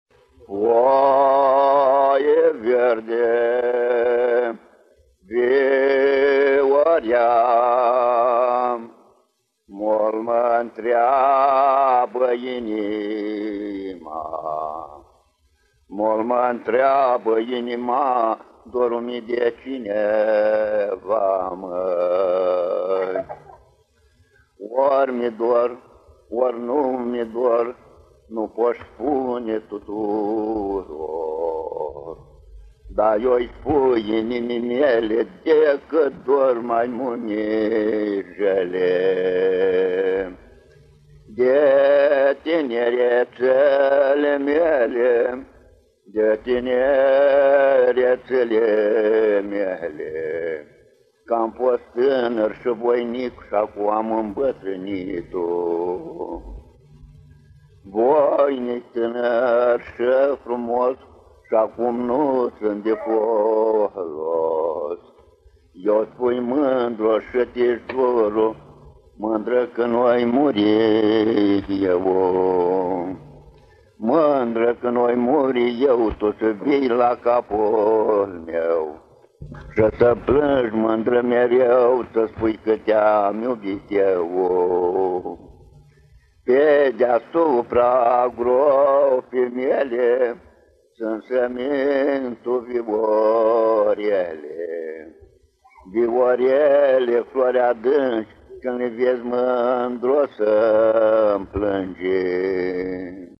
Următoarea doină pe care o difuzăm e interpretată de către rapsodul popular
doină înregistrată în anul 1970.